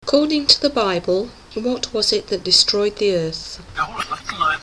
B300 and reversed Bulgarian background file